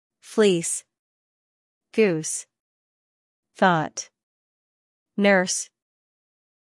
Long Vowels
/iː/ fleece Long “ee” with smile.
/uː/ goose Long “oo” with rounded lips.
long-vowels-IPA.mp3